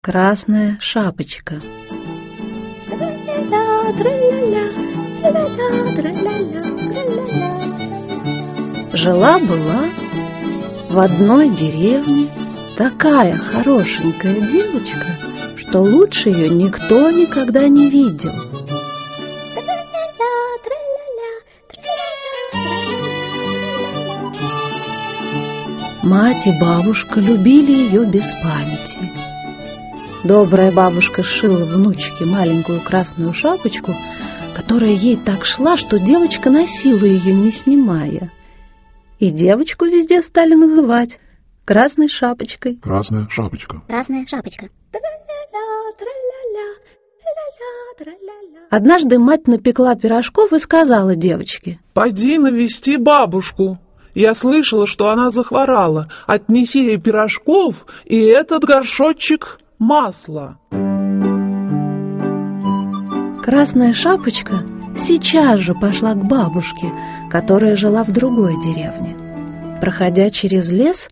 Аудиокнига Сказки для девочек | Библиотека аудиокниг
Aудиокнига Сказки для девочек Автор Ганс Христиан Андерсен Читает аудиокнигу Полина Кутепова.